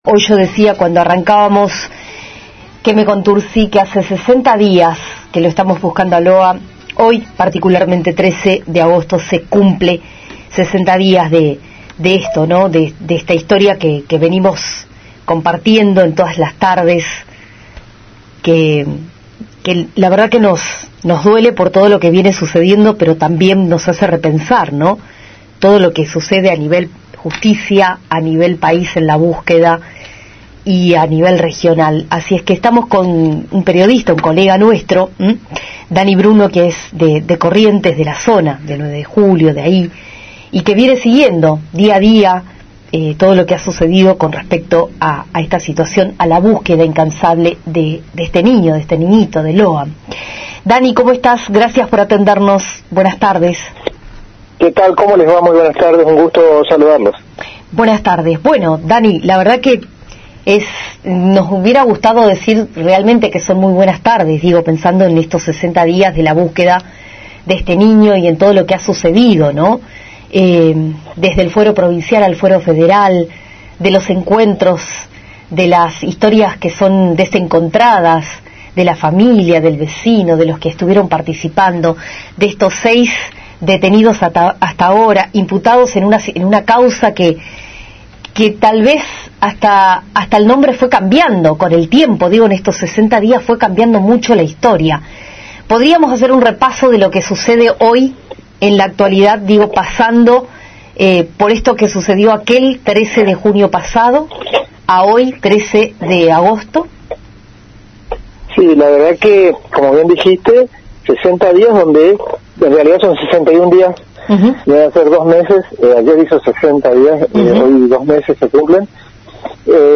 El periodista
charló con Radio Facundo Quiroga desde Corrientes